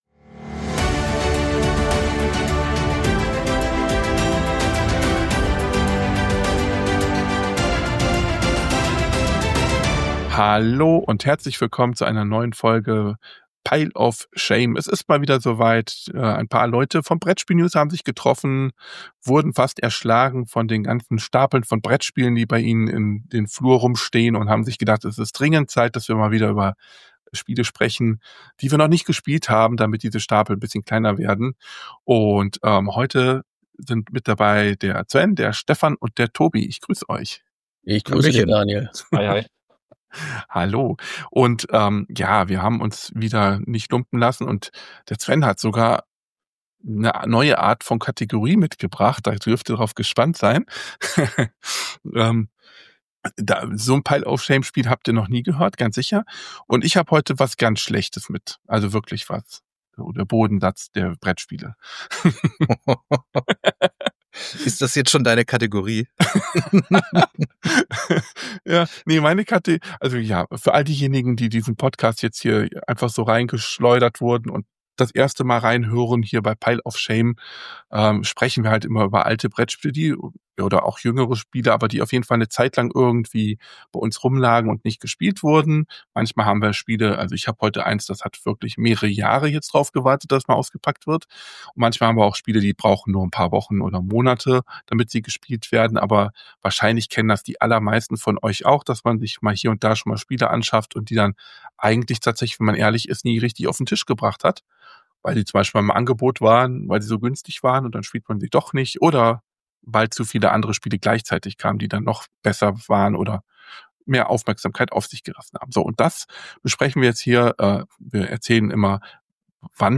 In einer inspirierenden und humorvollen Runde wird die Thematik der "Schamstapel" in Brettspielen behandelt – jene Spiele, die sich über Monate oder Jahre angesammelt haben, ohne je einen Tisch zu sehen.